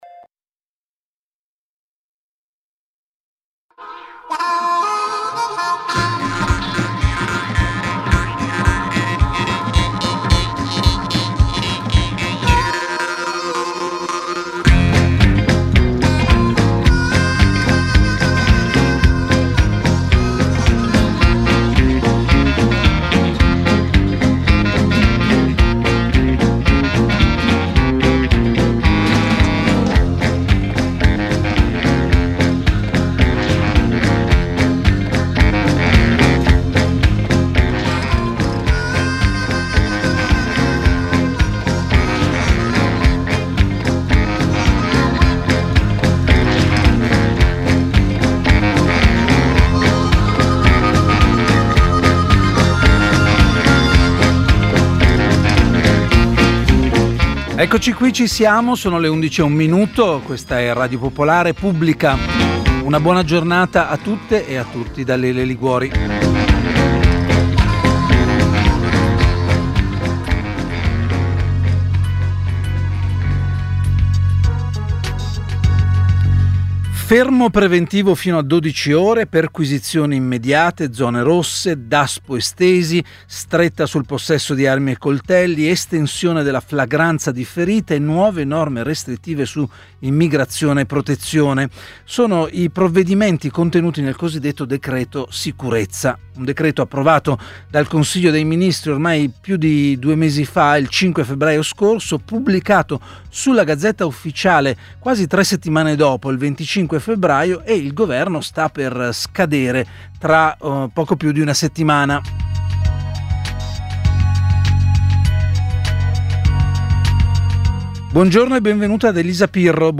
Per scoprire quale sarà l’iter del decreto, se ci saranno modifiche rispetto alla versione originaria, Pubblica ha ospitato Elisa Pirro, senatrice del M5S. Ospite anche l’ex magistrato Nello Rossi che ha definito il decreto Piantedosi un pezzo del diritto penale della destra.